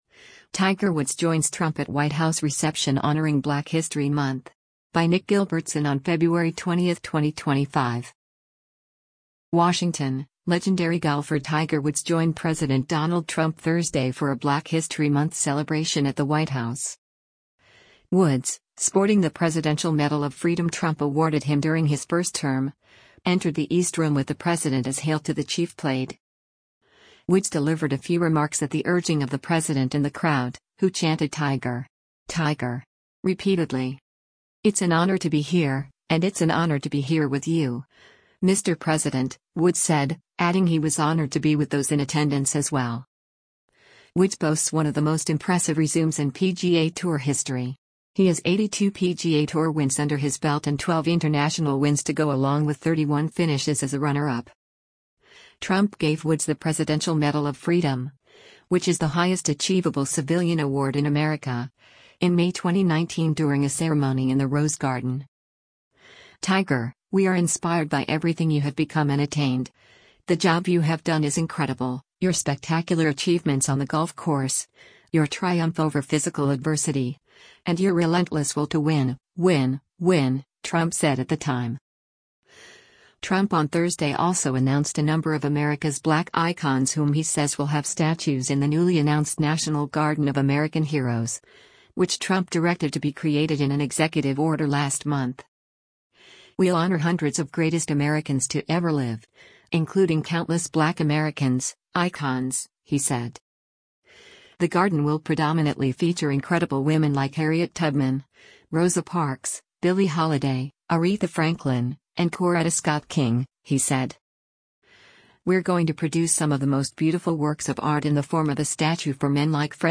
WASHINGTON – Legendary golfer Tiger Woods joined President Donald Trump Thursday for a Black History Month celebration at the White House.
Woods, sporting the Presidential Medal of Freedom Trump awarded him during his first term, entered the East Room with the President as “Hail to the Chief” played.
Woods delivered a few remarks at the urging of the president and the crowd, who chanted “Tiger! Tiger!” repeatedly.